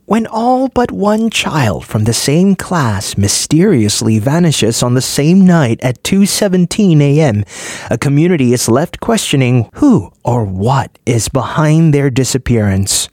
Voice Samples: Storytime (Clean VO)
male